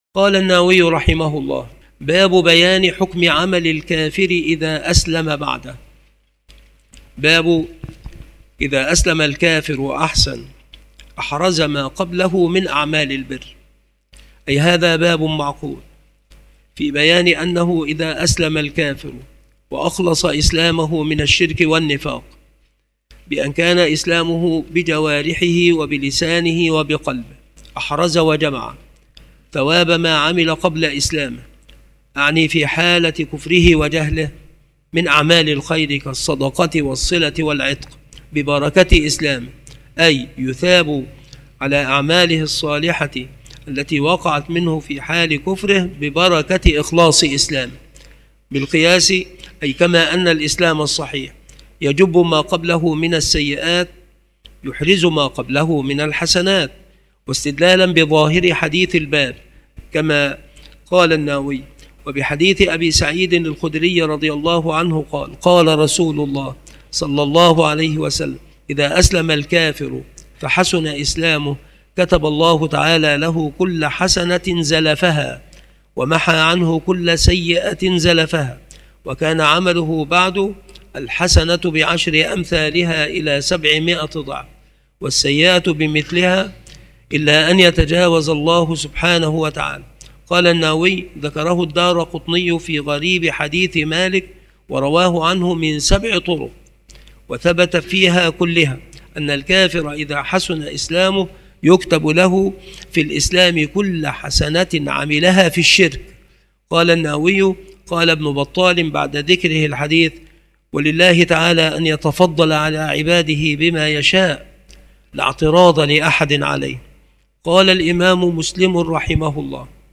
شروح الحديث
مكان إلقاء هذه المحاضرة بالمسجد الشرقي بسبك الأحد - أشمون - محافظة المنوفية - مصر